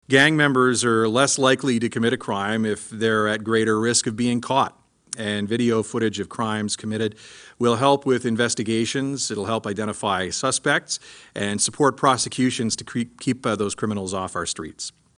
Smith told a press event that technology is one of the best tools police forces have to gain an advantage over criminals operating locally.